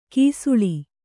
♪ kīsuḷi